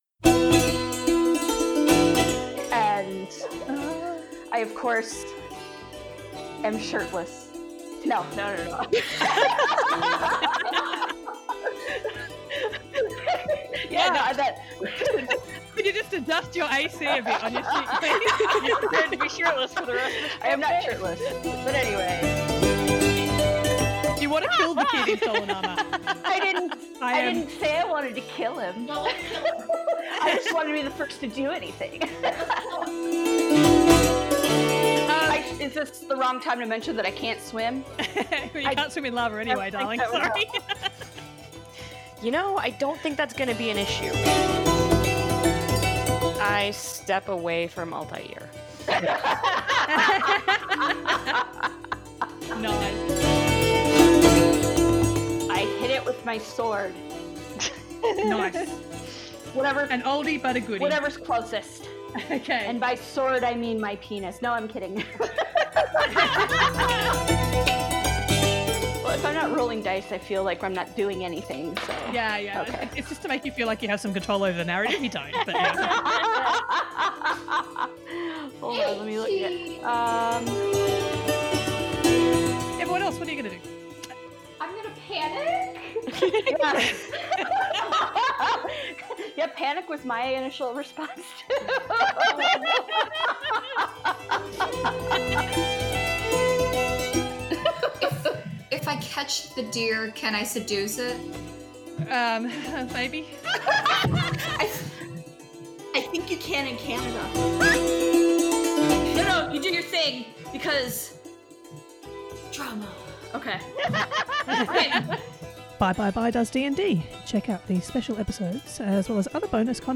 Do you like Actual Play Podcasts where you’re pretty sure you know more of the rules of the game than the DM?